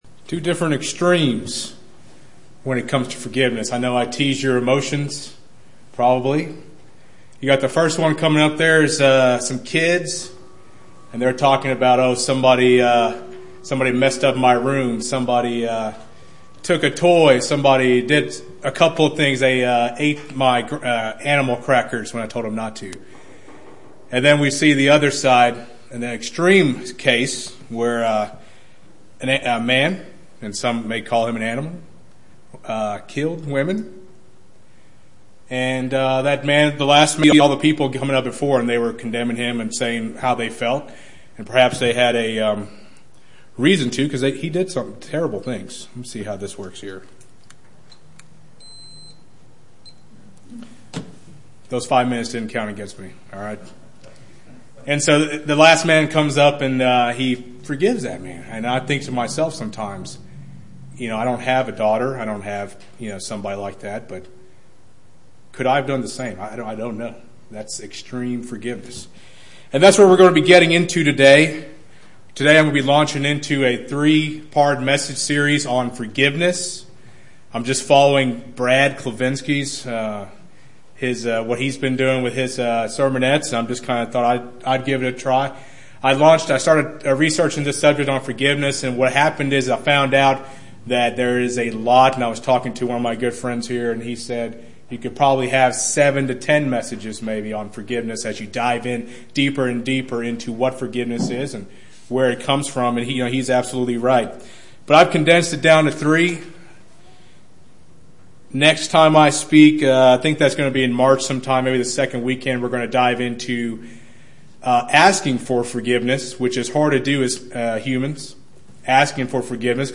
This is the first in a series of split sermons on forgiveness.